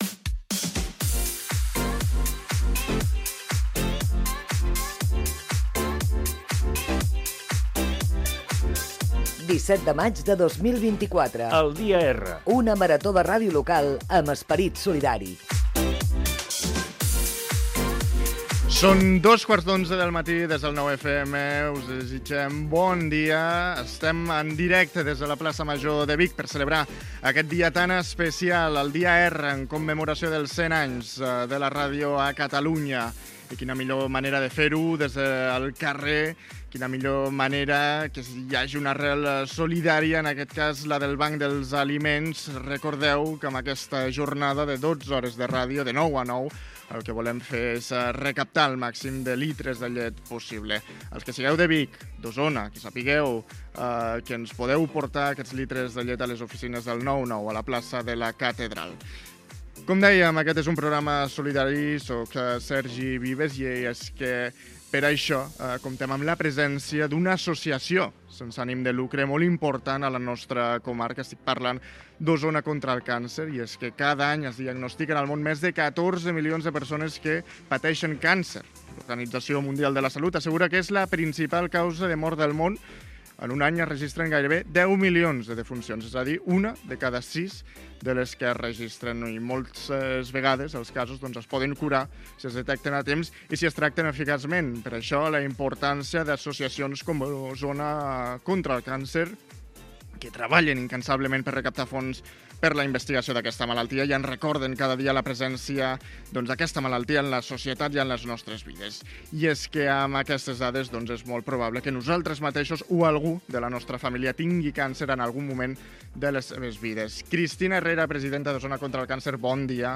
Data, indicatiu del programa, hora, franja feta des de la Plaça Major de Vic.
Entreteniment